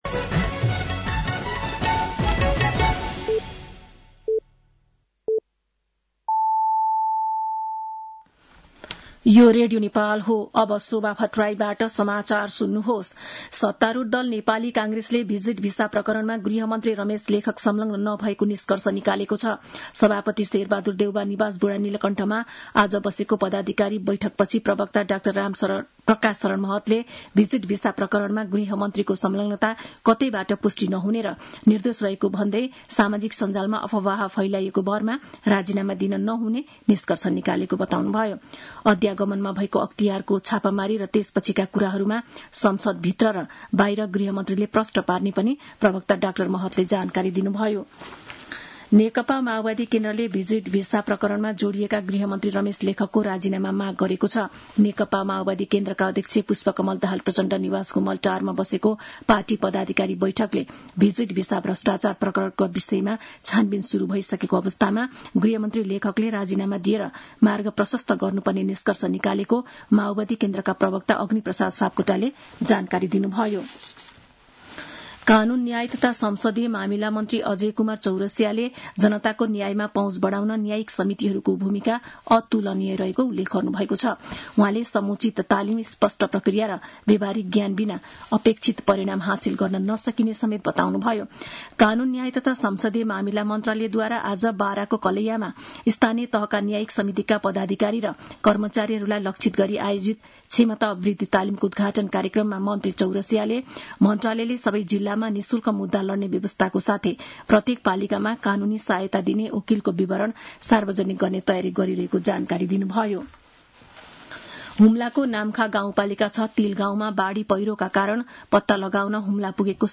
साँझ ५ बजेको नेपाली समाचार : १२ जेठ , २०८२
5.pm-nepali-news-1-2.mp3